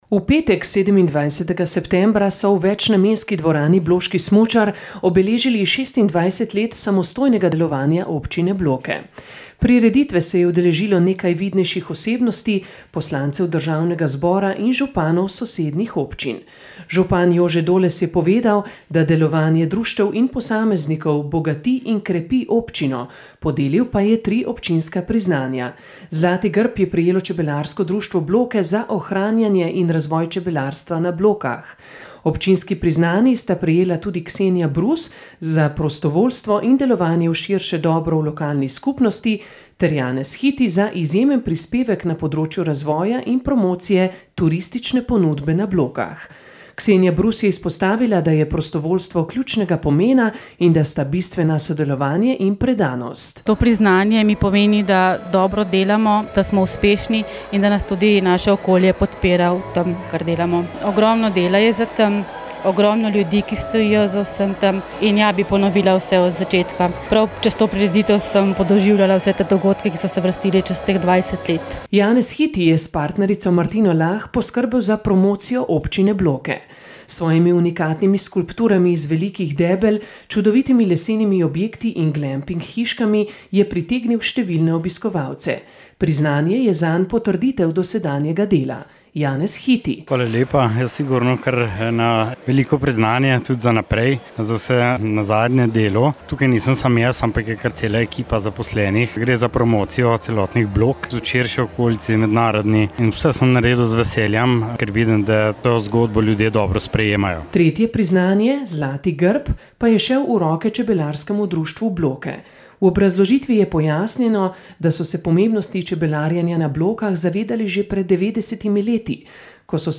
V petek, 27.9., so v večnamenski dvorani Bloški smučar obeležili 26 let samostojnega delovanja občine Bloke.
Župan Jože Doles je povedal, da delovanje društev in posameznikov bogati in krepi občino, podelil pa je tri občinska priznanja.